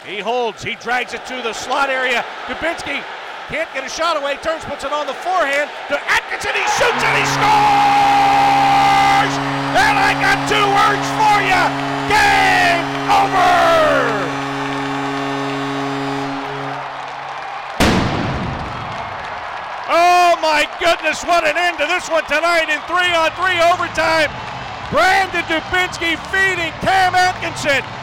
Cam Atkinson scores the GAME WINNER in Overtime against the Pittsburgh Penguins inside Nationwide Arena!!!!